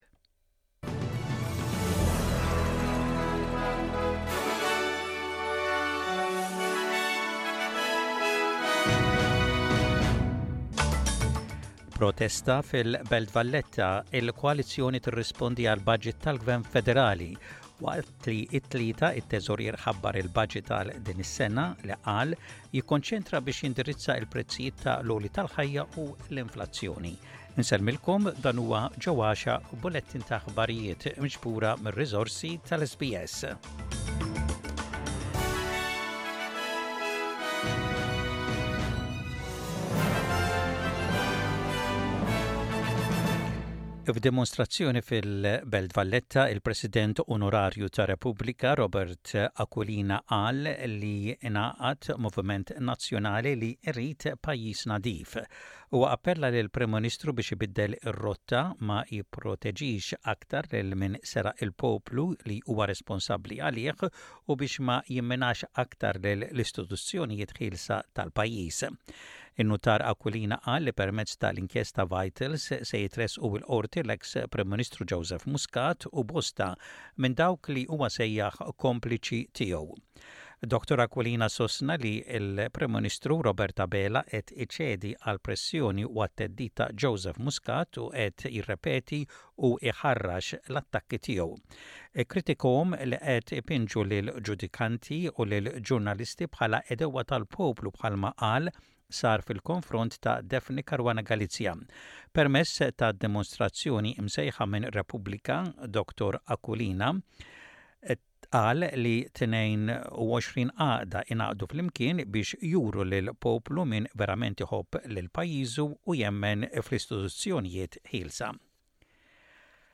SBS Radio | Maltese News: 17.05.24